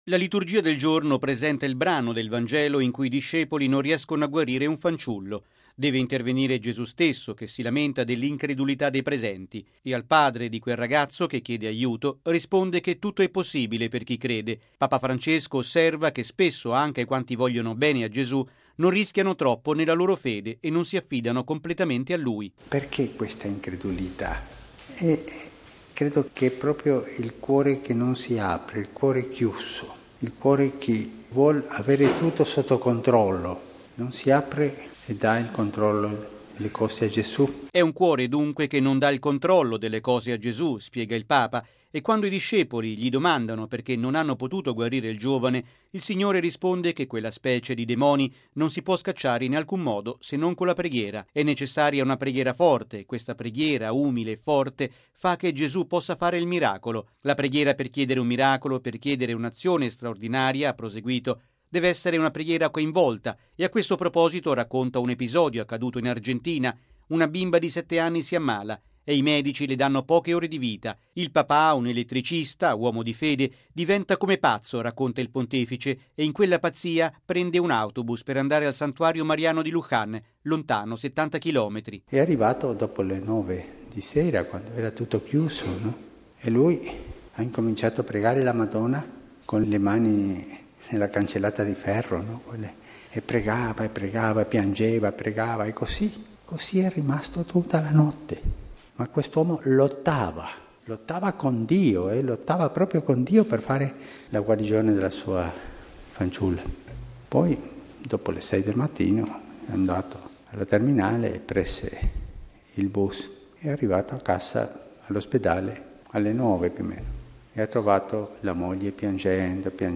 ◊   Una preghiera coraggiosa, umile e forte, compie miracoli: è quanto ha affermato il Papa stamani nella Messa presieduta a Santa Marta.